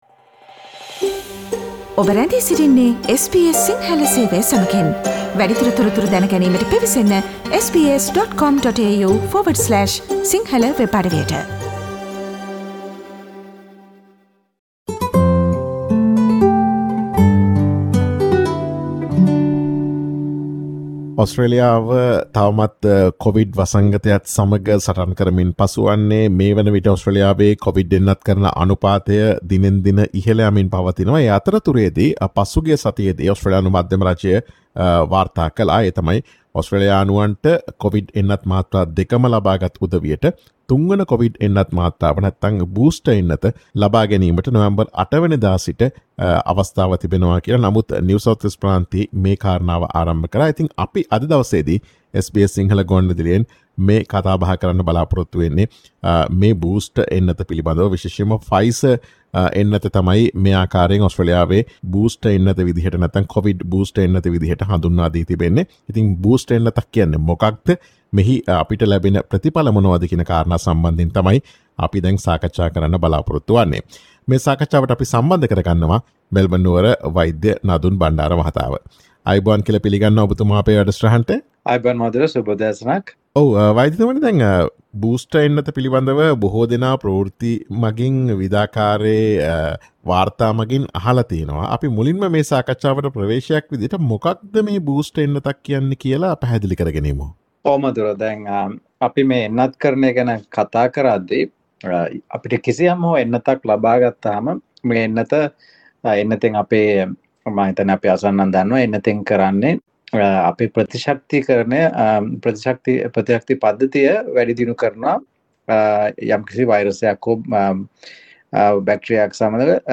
ඔස්ට්‍රේලියාවේ කොවිඩ් බූස්ටර් එන්නත ලබාගත හැකි අයුරු පිළිබඳ SBS සිංහල ගුවන් විදුලිය සිදුකළ සාකච්ඡාවට සවන් දෙන්න.